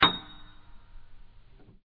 ClickNormal.mp3